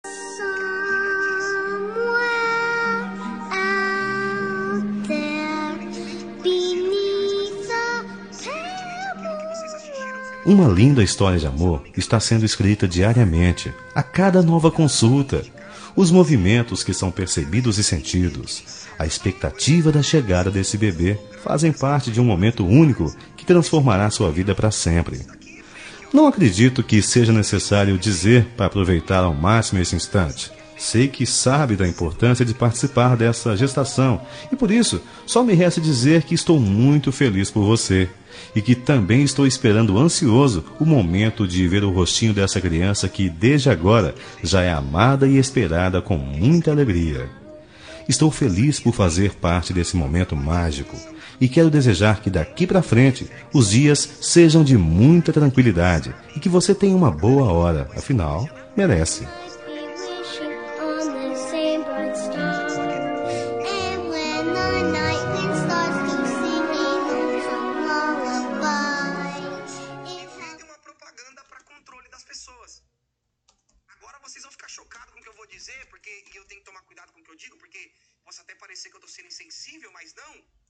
Telemensagem de Gestante – Voz Masculina – Cód: 6638